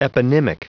Prononciation du mot eponymic en anglais (fichier audio)
Prononciation du mot : eponymic